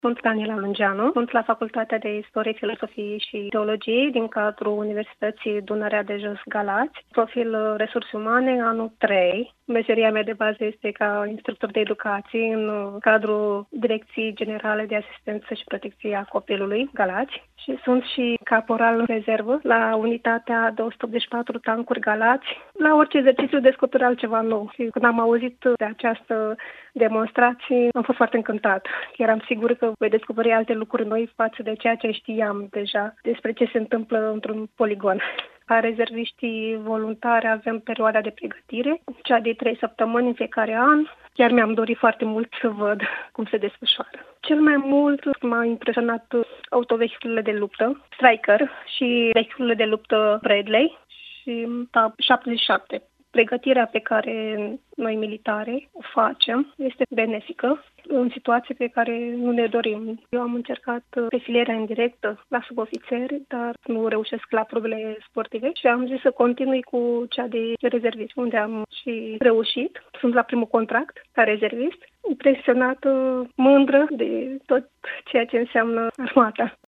Despre experiența trăită într-un poligon militar, trei dintre studenții prezenți ne-au împărtășit opinia lor despre cele văzute în poligonul Smârdan.